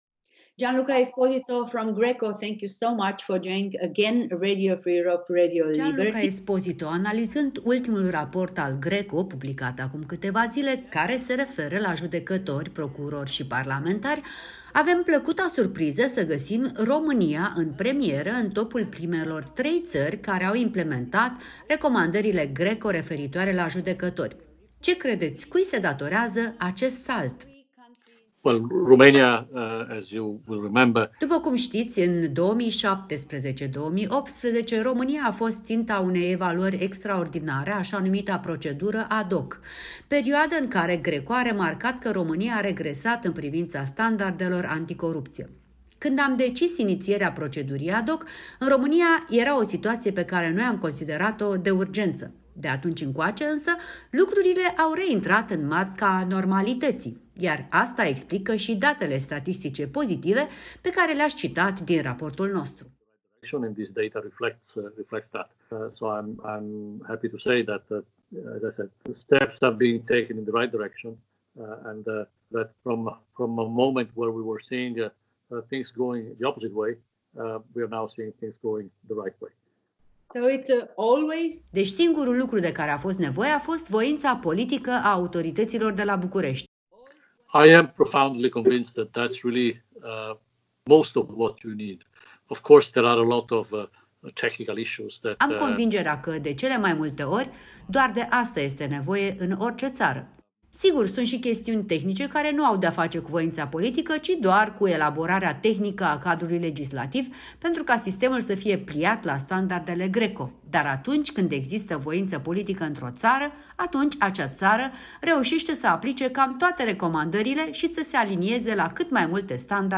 Unele guverne iau măsuri anti-corupție după ce izbucnește un scandal, spune într-un interviu cu Europa Liberă directorul executiv al GRECO, Gianluca Esposito.